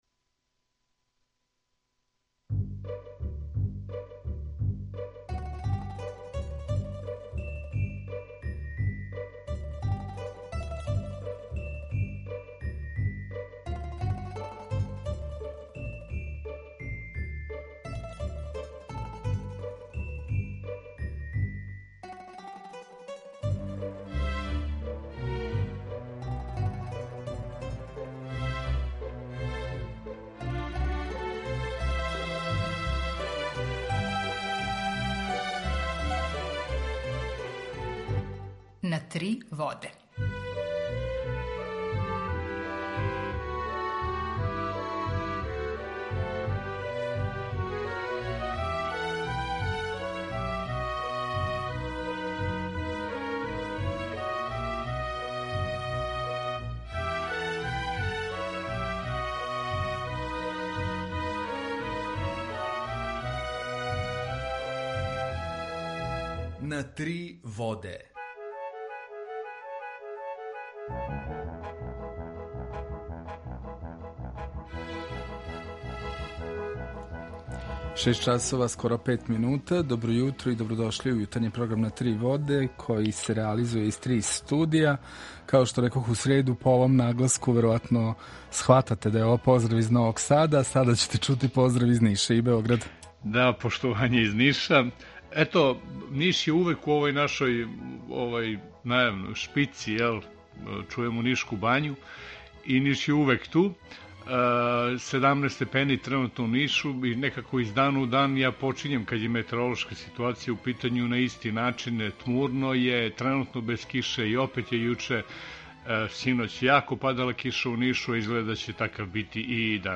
Емисију реализујемо заједно са студиом Радија Републике Српске у Бањалуци и са Радио Новим Садом.
Јутарњи програм из три студија
У два сата, ту је и добра музика, другачија у односу на остале радио-станице.